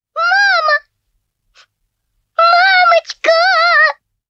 • Качество: высокое
Здесь вы найдете знаменитые песни, смешные диалоги и фразы персонажей в отличном качестве.
Звук Пяточка из мультфильма Мама, мамочка